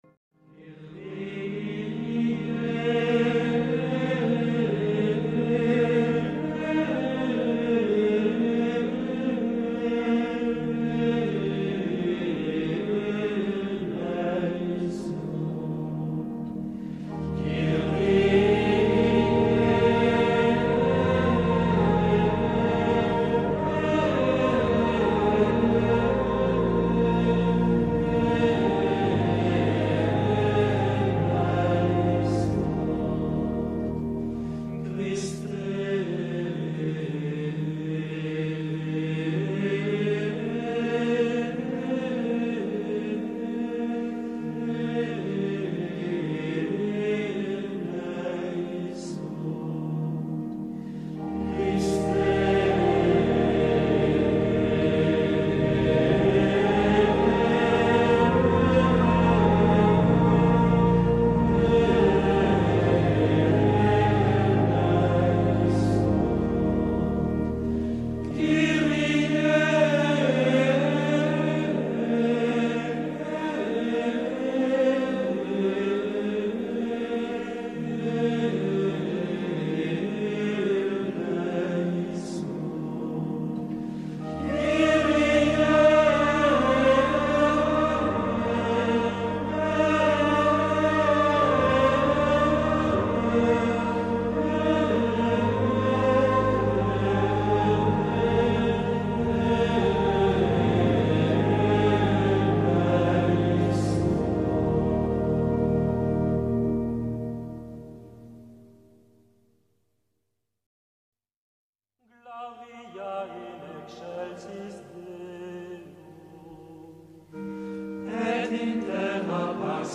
마음이 편안해지는 그레고리안 미사곡(4곡)
이 음악은 중세시대로 안내합니다.
마음이 평안해지는 그레고리안 성가 스트리밍입니다.